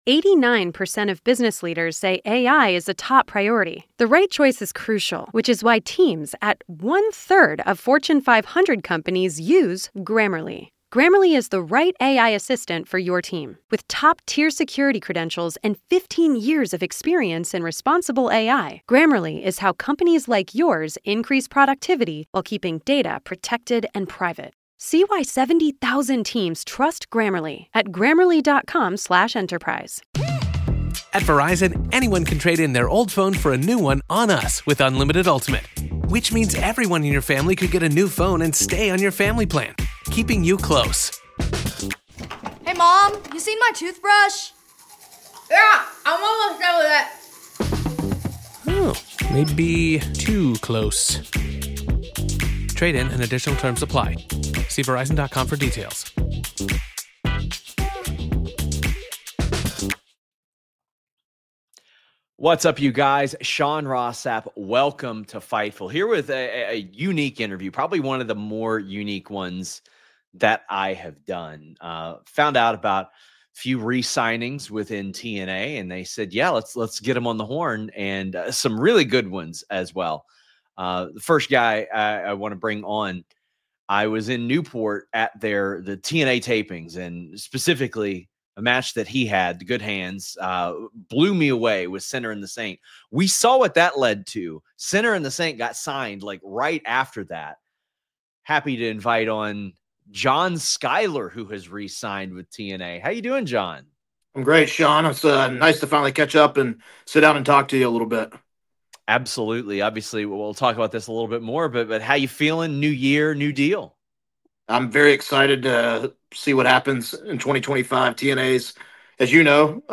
Interview
Shoot Interviews Jan 6